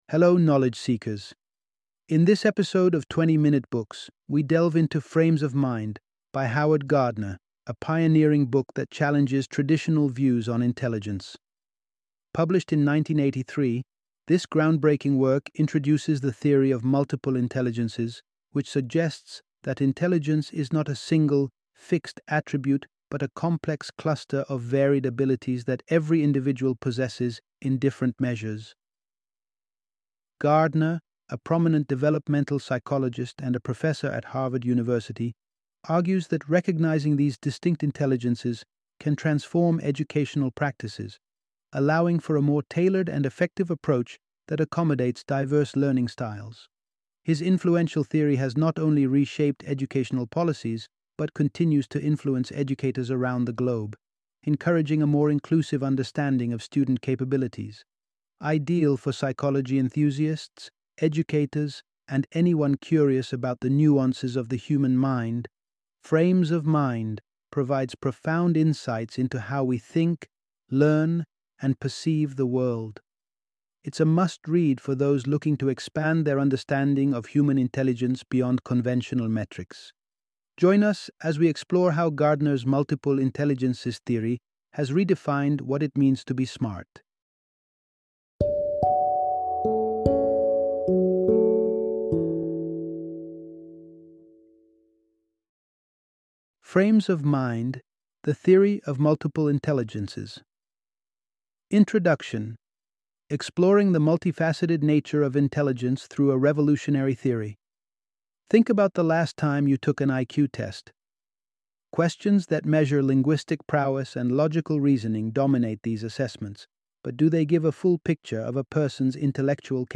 Frames of Mind - Audiobook Summary